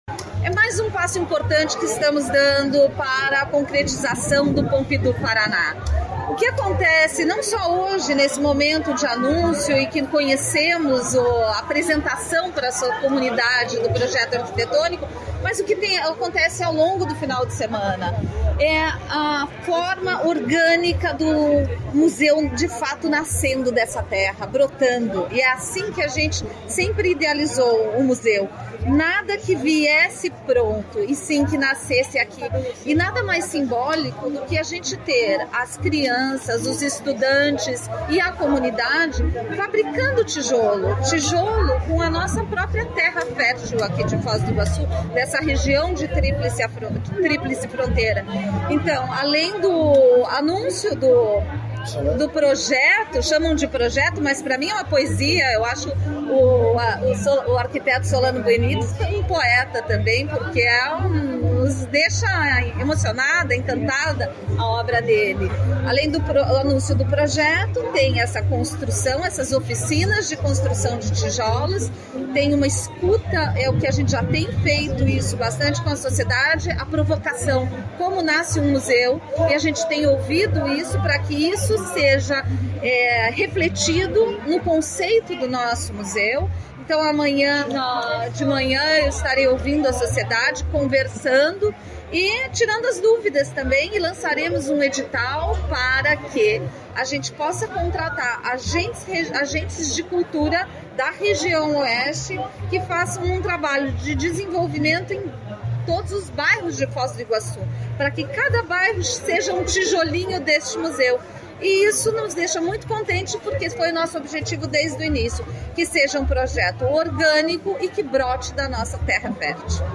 Sonora da secretária da Cultura, Luciana Casagrande Pereira, sobre a apresentação do Centre Pompidou Paraná